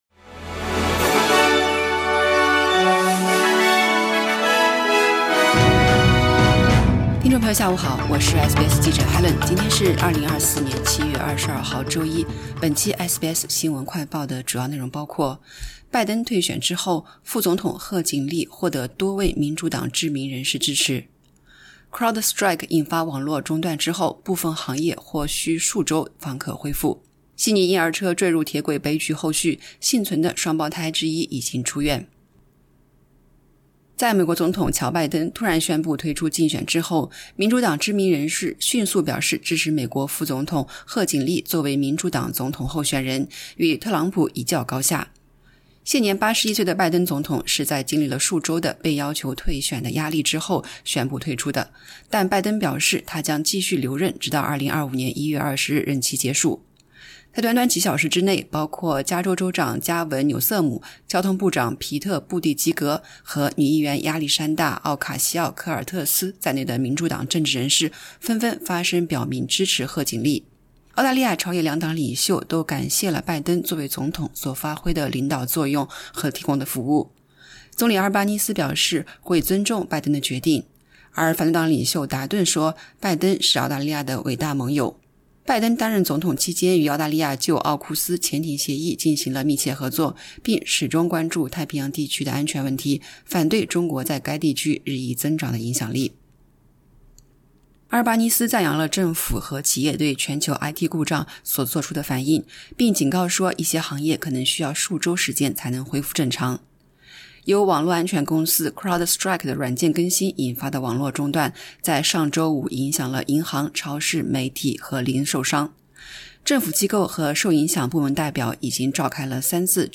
【SBS新闻快报】悉尼婴儿车坠入铁轨悲剧后续：幸存幼儿已出院